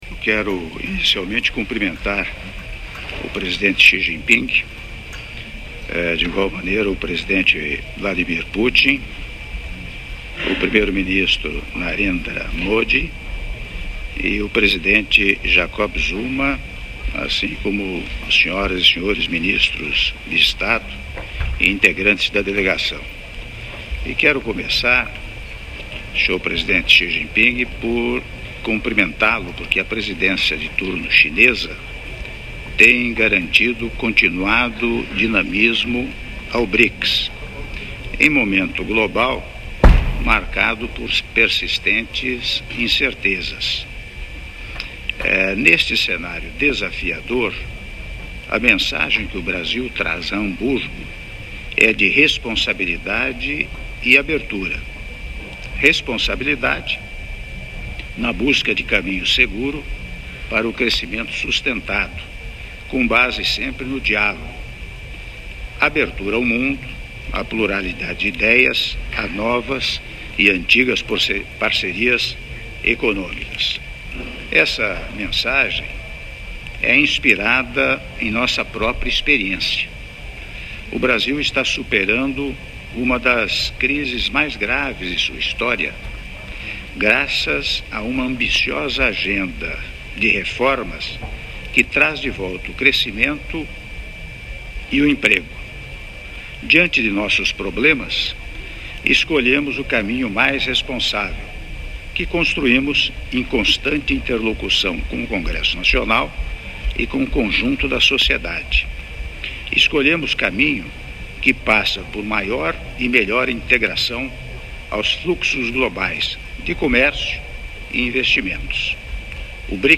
Áudio do Discurso do Presidente da República, Michel Temer, durante a reunião dos Chefes de Estado e de Governo do BRICS - (04min39s) - Hamburgo/Alemanha